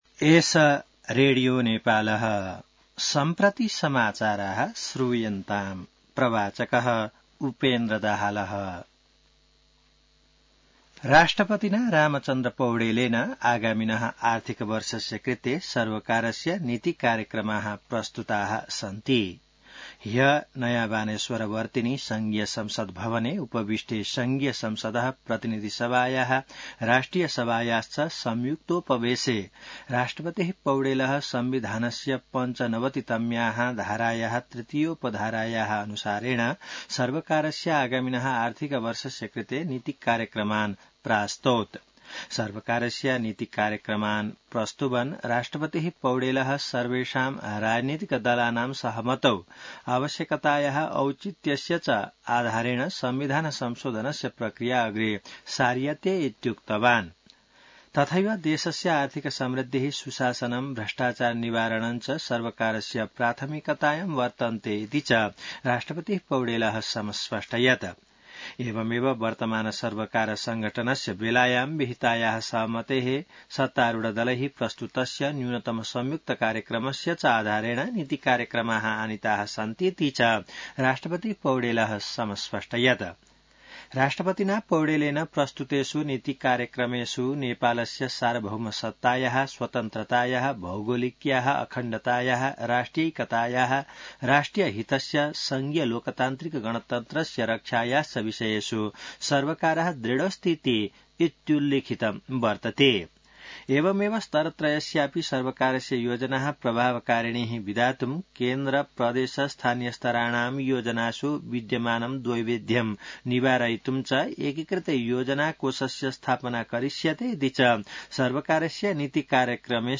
संस्कृत समाचार : २० वैशाख , २०८२